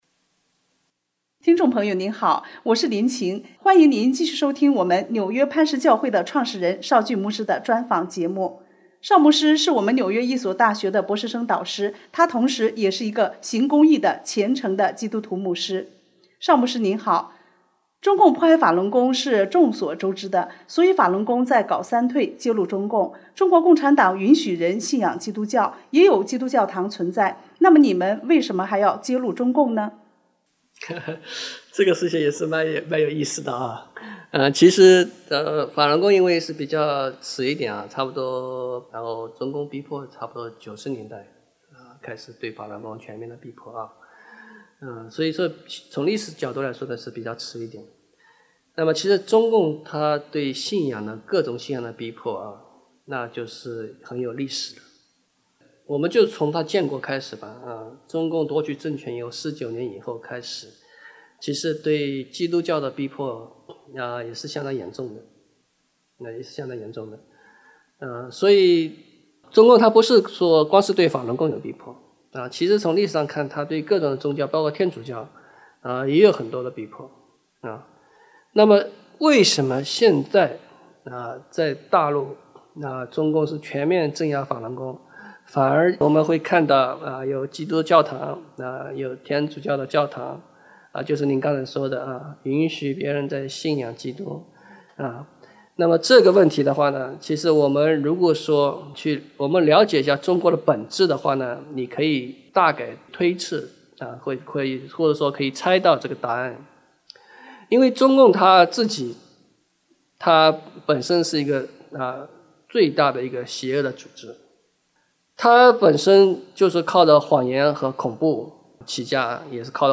主持人：听众朋友好，欢迎您继续收听我们纽